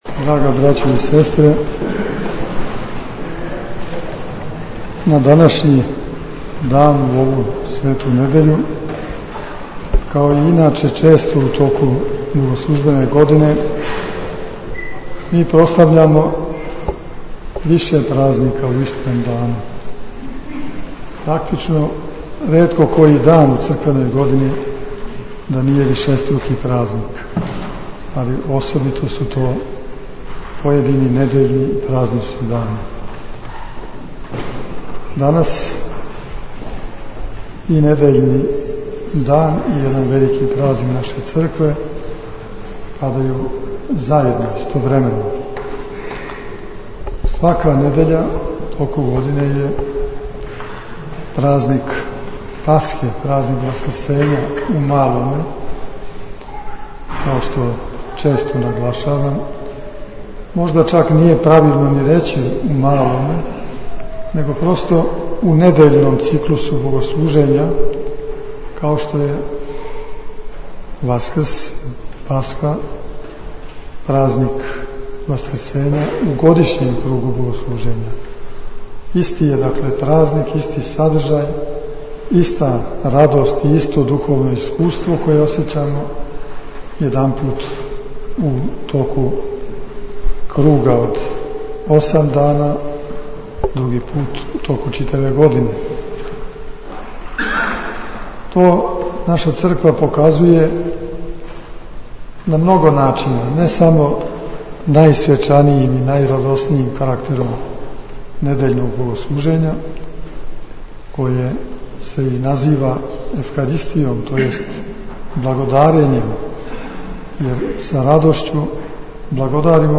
• Беседа Епископа Иринеја на светој Литургији у новосадском Саборном храму: